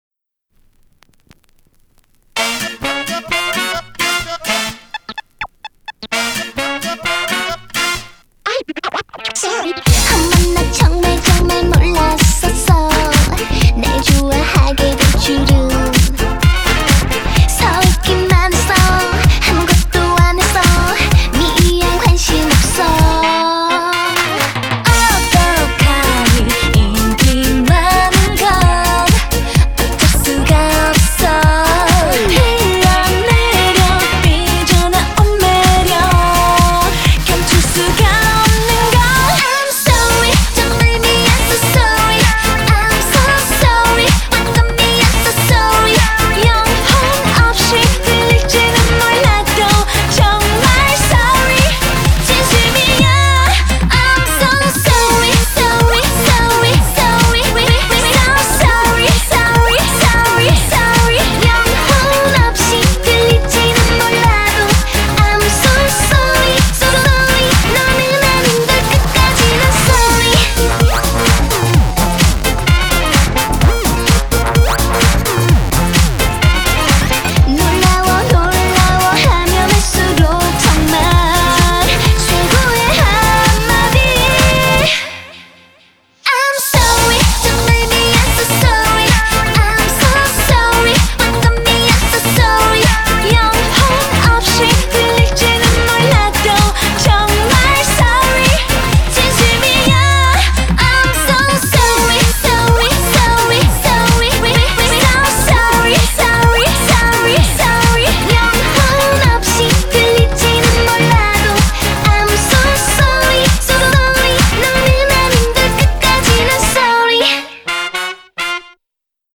BPM128
Audio QualityPerfect (High Quality)
Anyway, super cute song.